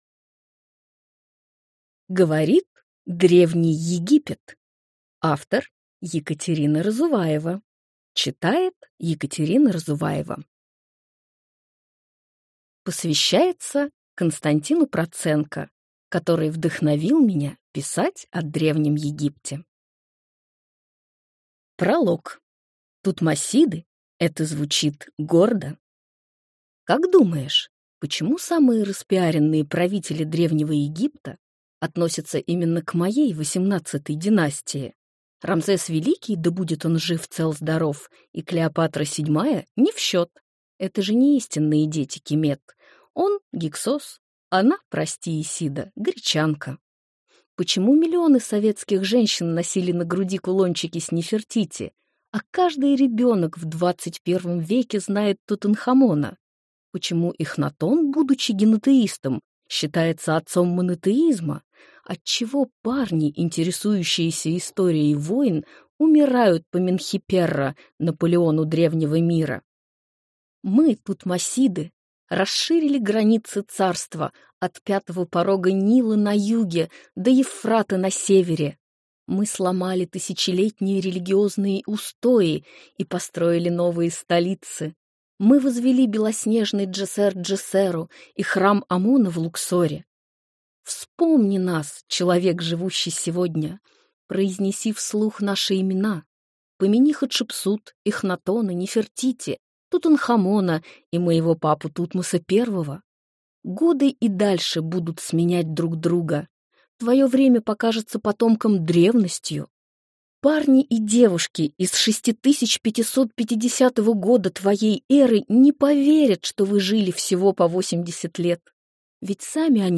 Аудиокнига Говорит Древний Египет | Библиотека аудиокниг